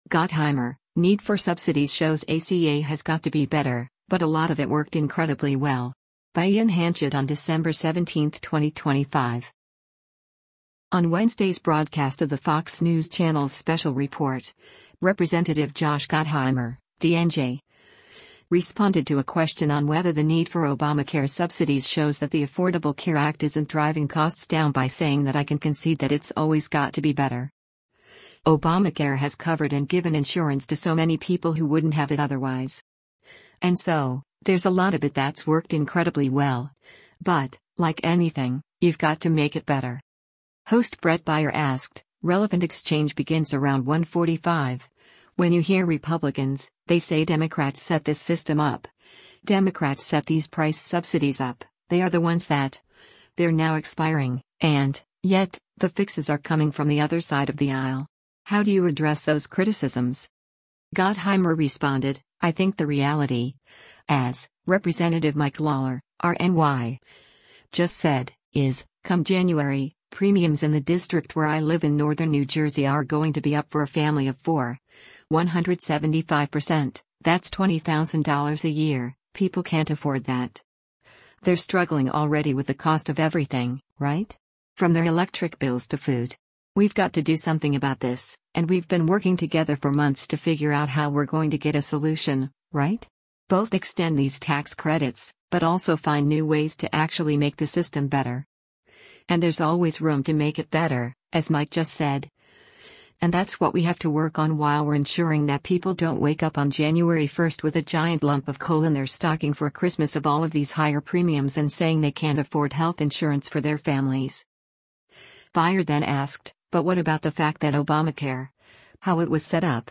On Wednesday’s broadcast of the Fox News Channel’s “Special Report,” Rep. Josh Gottheimer (D-NJ) responded to a question on whether the need for Obamacare subsidies shows that the Affordable Care Act isn’t driving costs down by saying that “I can concede that it’s always got to be better.